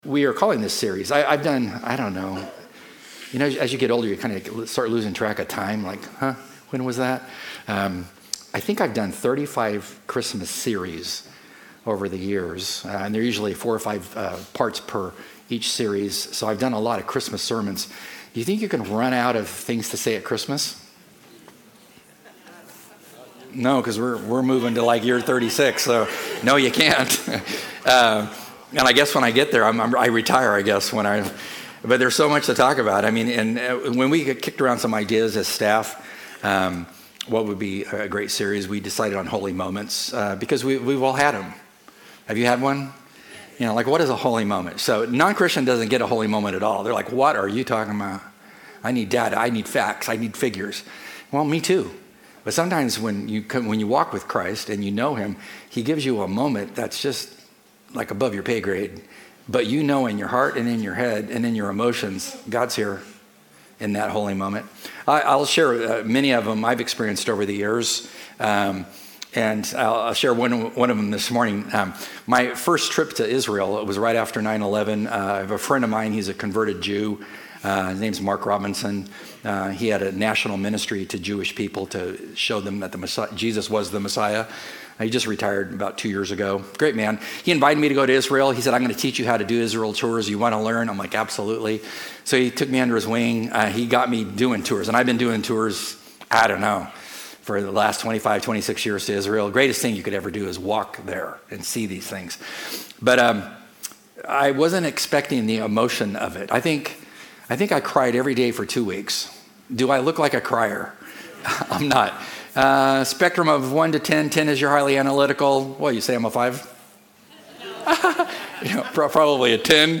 Join us as we kick off the Christmas season with our "Holy Moments" sermon series. Take in the sights and sounds of the season and most of all lean into the miracle of Jesus Christ and the difference he makes in our lives and in our world.